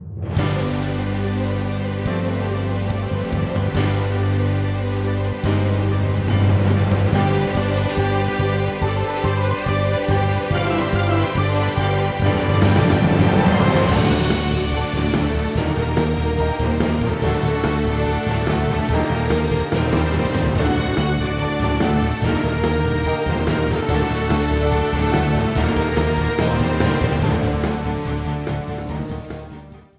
Trimmed and fade out
Fair use music sample